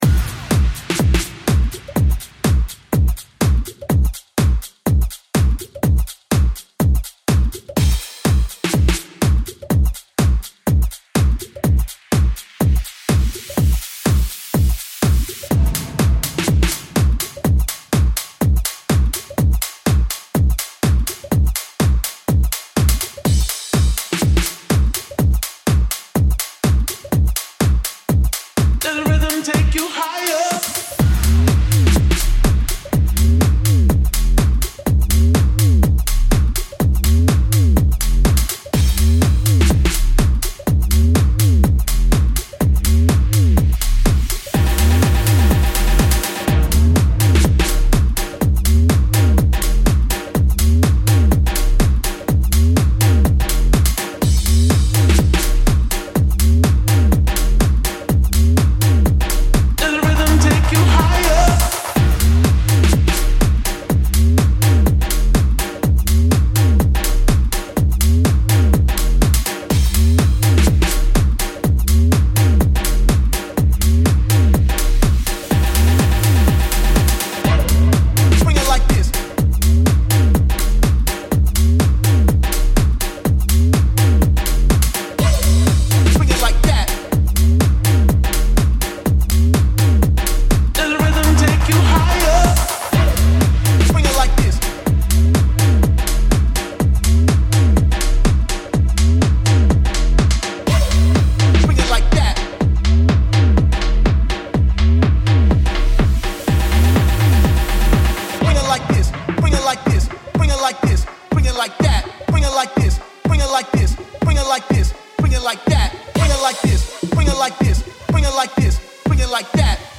Tech House of the now!